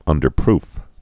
(ŭndər-prf)